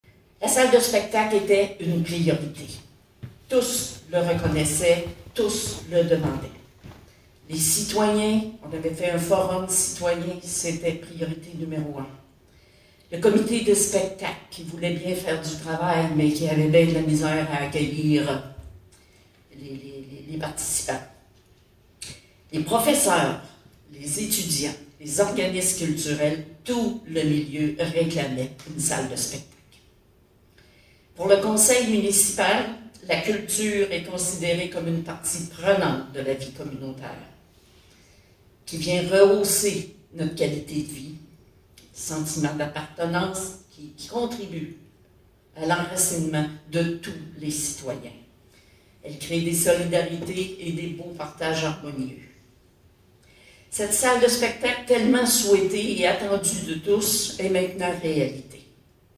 Lundi 27 septembre dernier avait lieu l’inauguration du Pavillon des Arts de Forestville.
Madame Micheline Anctil, mairesse de Forestville ainsi que pionnière tout aussi importante du projet était très émue de cette inauguration tant attendue: